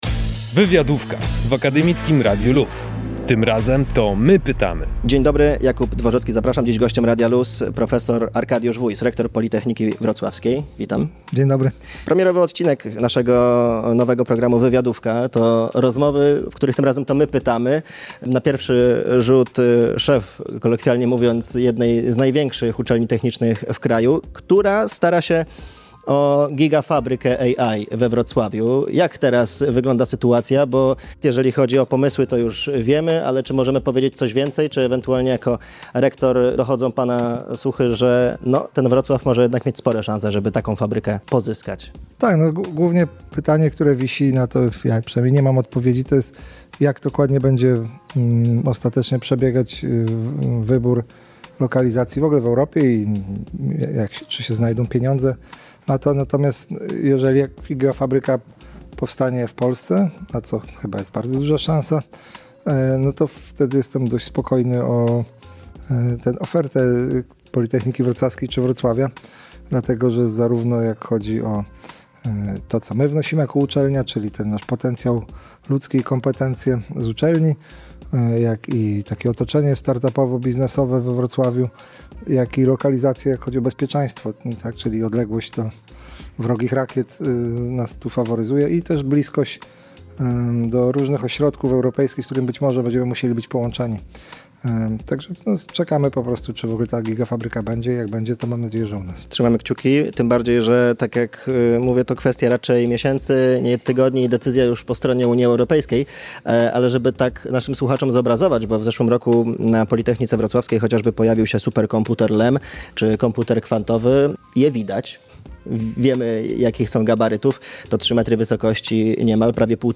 O tym, a także o konferencji LEM Next Gen Science oraz zmianach na kampusie Politechniki Wrocławskiej mówi w rozmowie dla Radia LUZ rektor uczelni, prof. Arkadiusz Wójs.